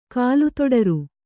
♪ kālu toḍaru